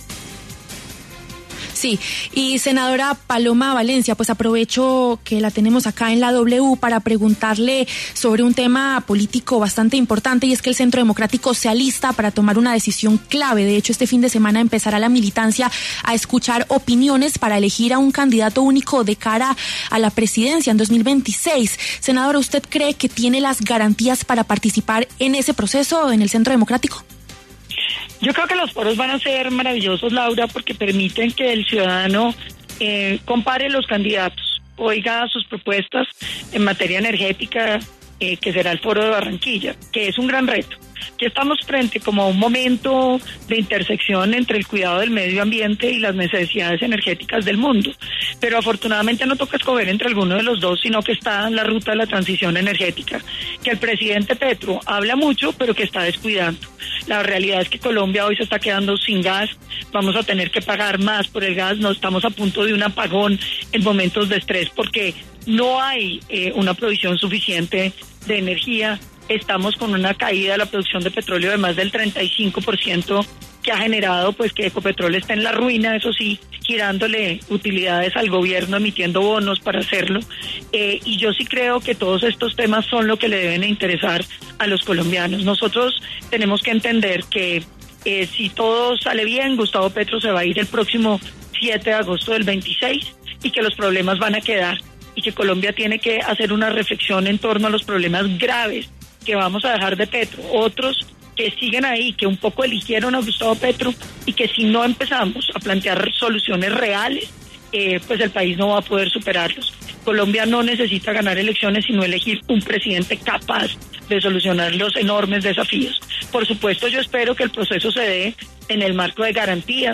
Paloma Valencia, senadora de la colectividad, conversó con La W sobre el posible candidato único para las presidenciales y la gira que planean hacer para que los ciudadanos conozcan las propuestas.